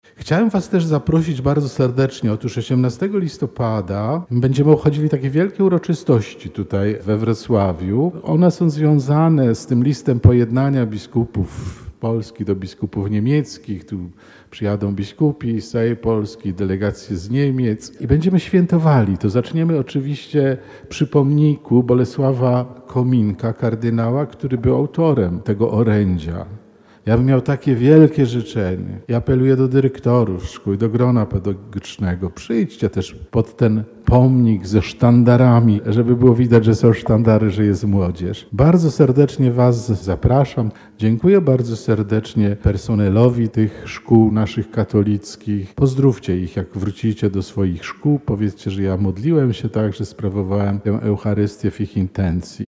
Mszą Św. w Katedrze Wrocławskiej rozpoczęła się inauguracja roku szkolnego 2025/2026 dla placówek katolickich Archidiecezji Wrocławskiej.